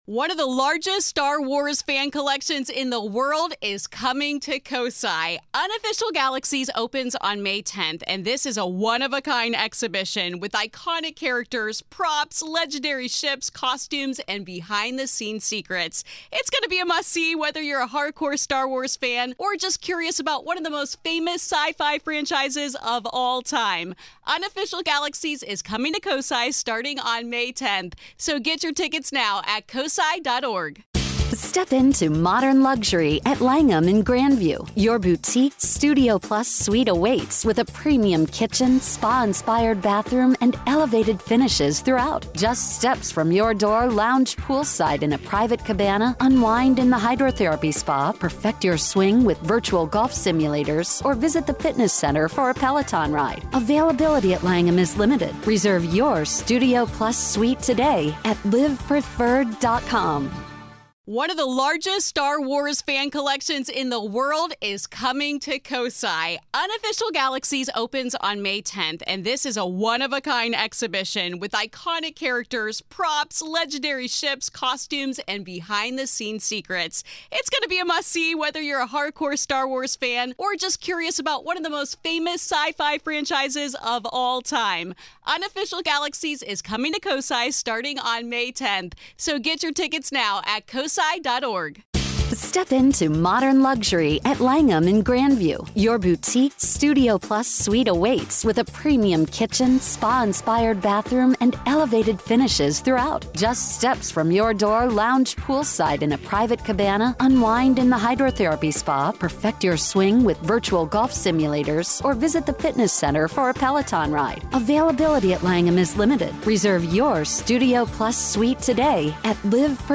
Join us as former staff, historians, and paranormal investigators revisit a place where the lights went out—but the stories never did. This is Part Two of our conversation.